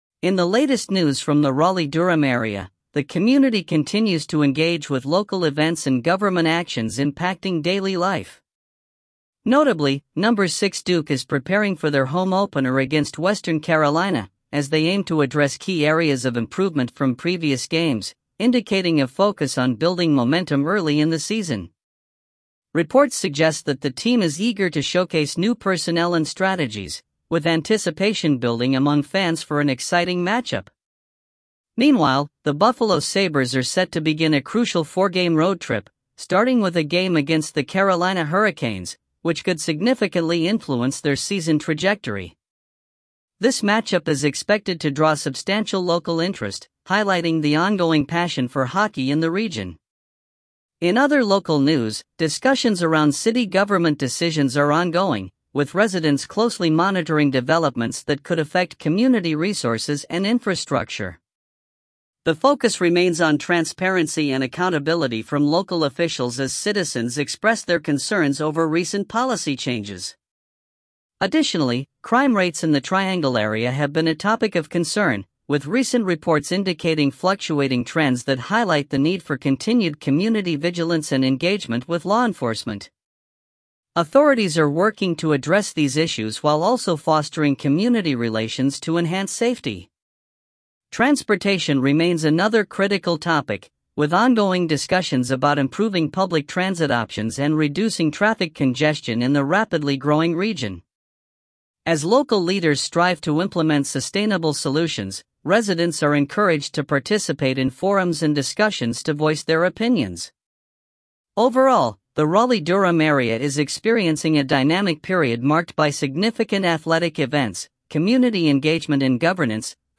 Raleigh-Durham News Summary